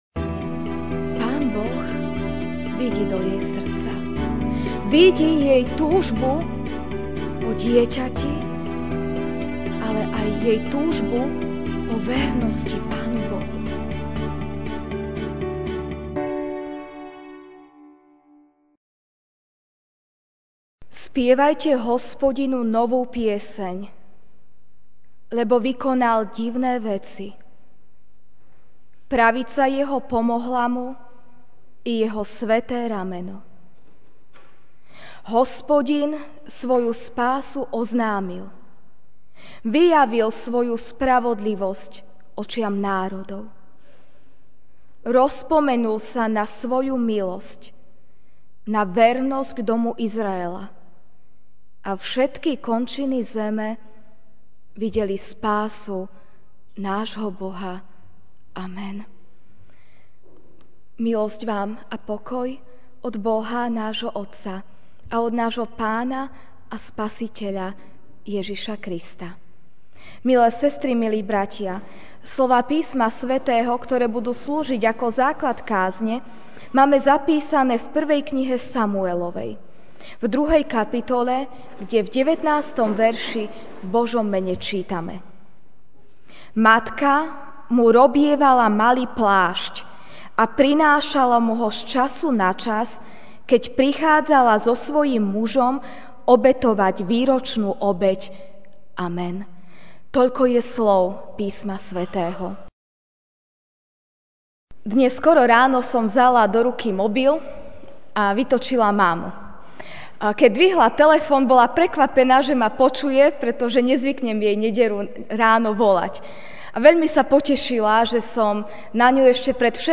máj 14, 2017 Deň matiek MP3 SUBSCRIBE on iTunes(Podcast) Notes Sermons in this Series Večerná kázeň: Deň matiek (1. Sam. 2, 19) Matka mu robievala malý plášť a prinášala mu ho z času na čas, keď prichádzala so svojím mužom obetovať výročnú obeť.